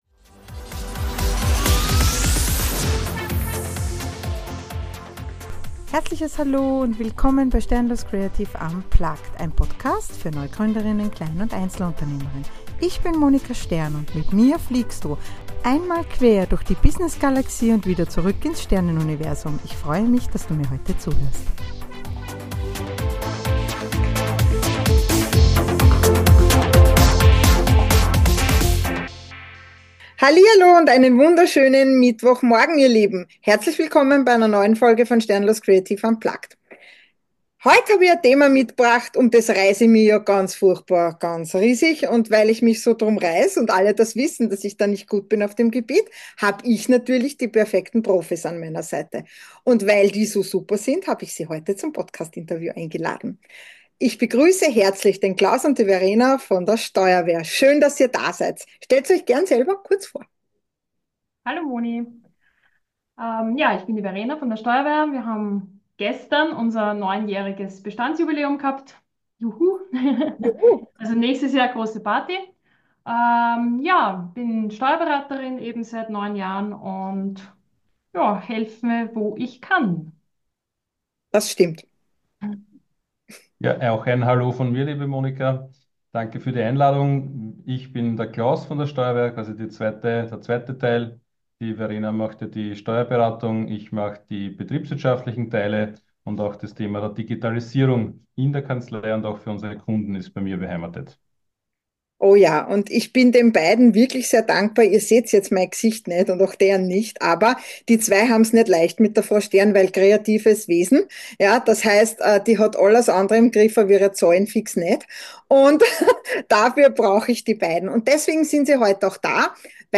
Sternenfolgen-89 Buchaltung, Steuern und neugründen – Interview steuerwehr ~ sternloscreative – unplugged Podcast
Wir plaudern ehrlich, herzlich und mit einem Augenzwinkern über Geschäftskonten, Umsatzsteuergrenzen und warum der Businessplan dein bester Freund ist.